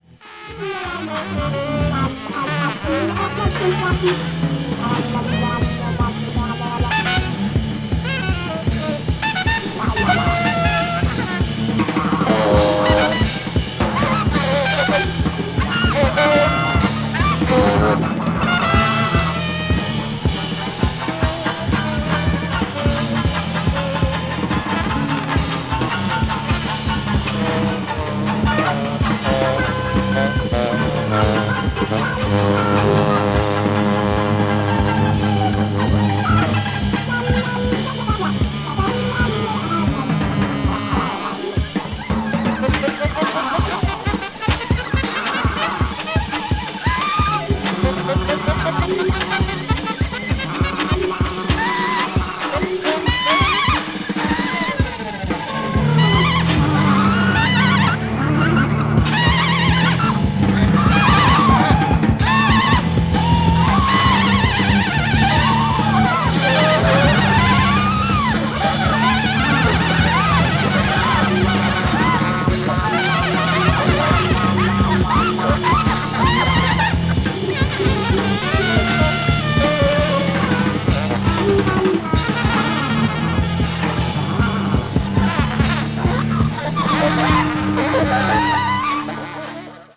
trumpet
trombone
cello
drums
avant-jazz   free improvisation   free jazz   jazz funk